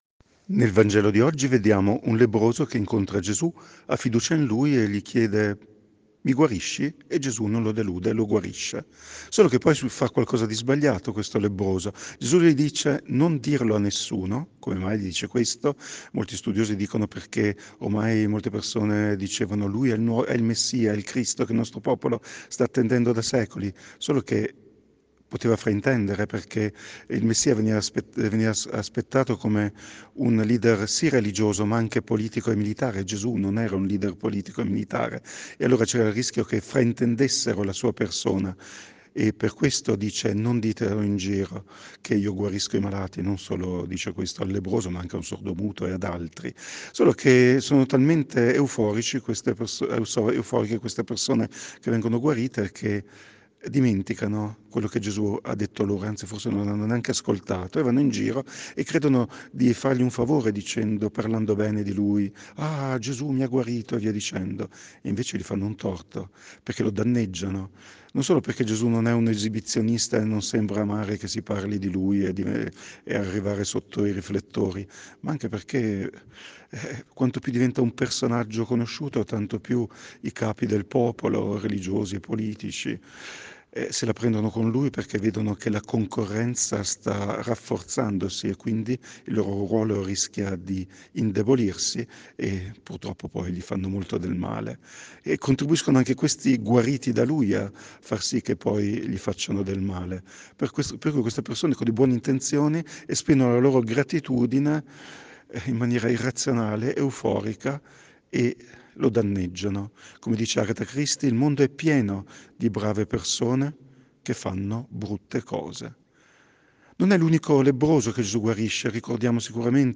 Meditazione Domenica 11 Febbraio 2024 – Parrocchia di San Giuseppe Rovereto